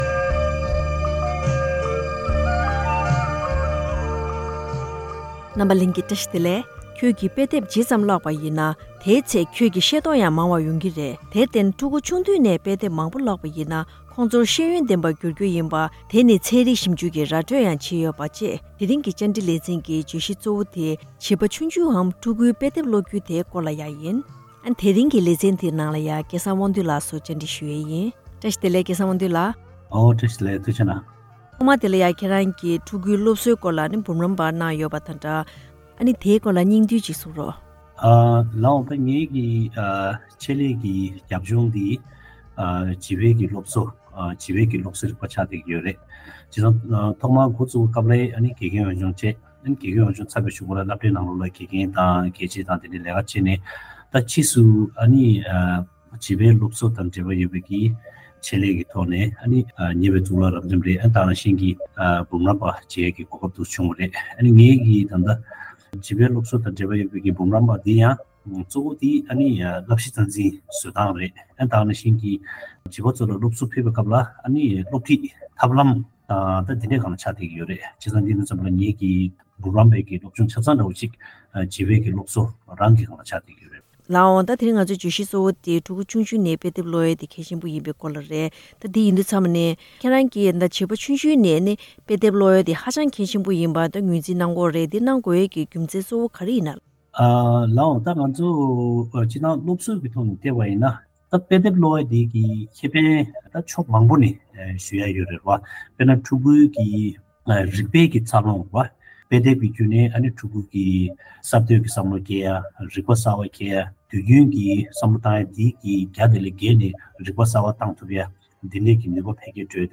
བཅར་འདྲི་ལེ་ཚན་